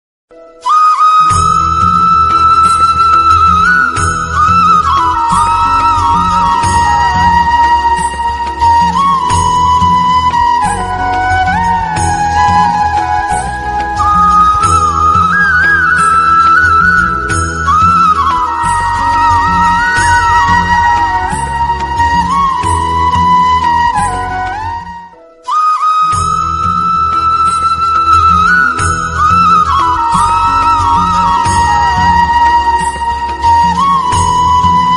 Krishna Bansuri Ringtone Download.